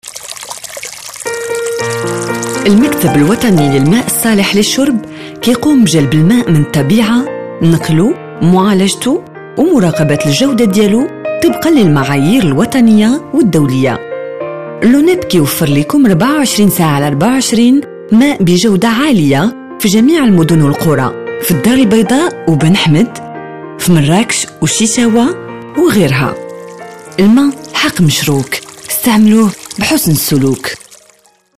Un (01) spots Radio :